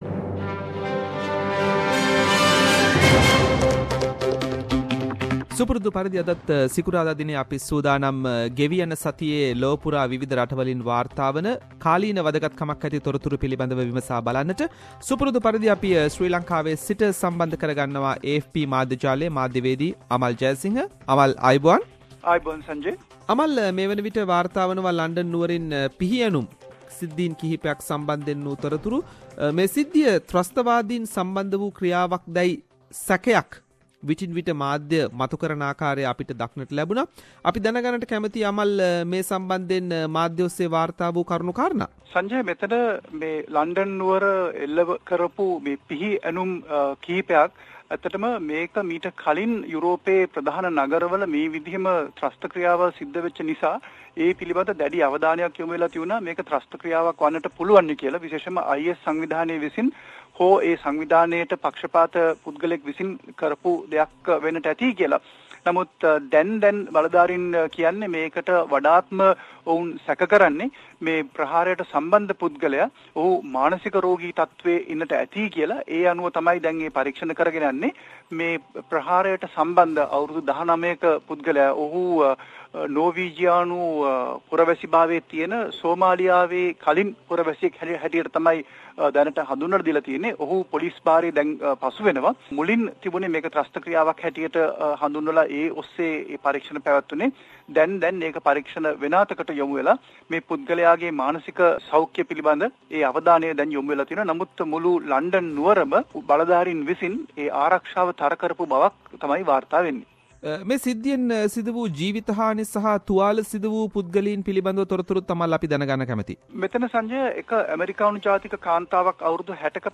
“SBS Sinhala” Around the World – Weekly World News highlights…..